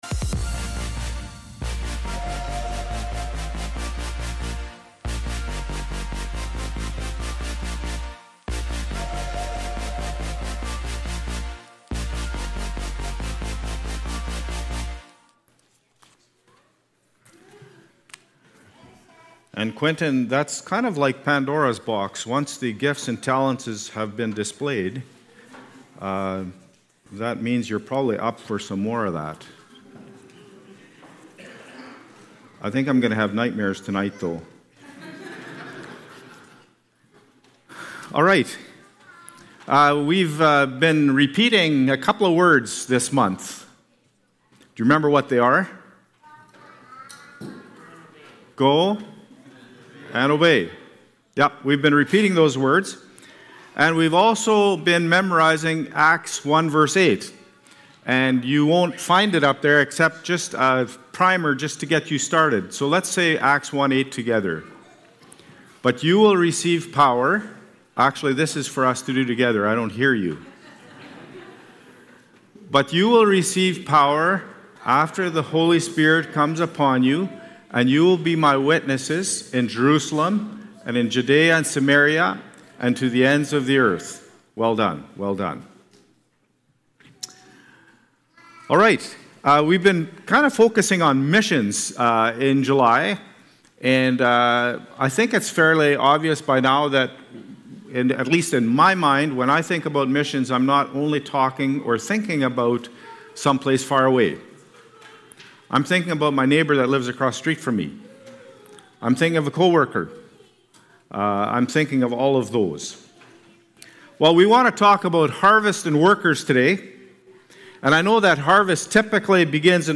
July-20-Worship-Service.mp3